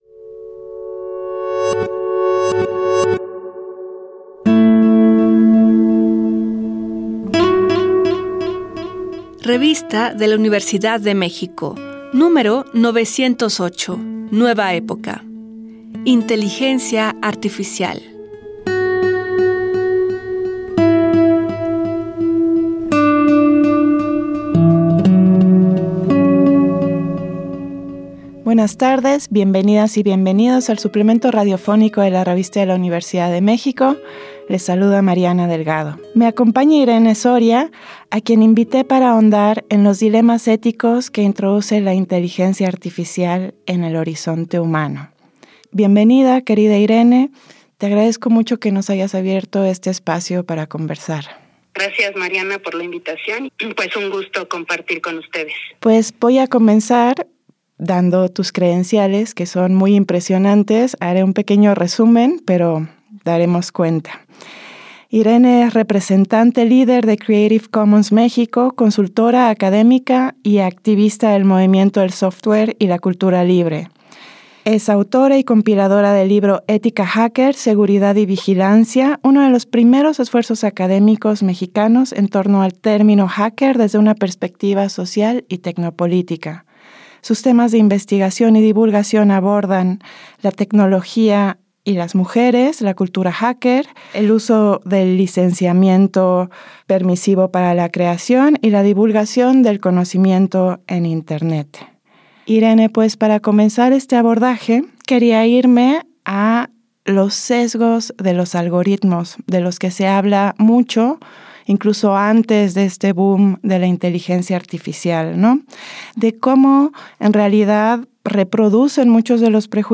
Fue transmitido el jueves 30 de mayo de 2024 por el 96.1 FM.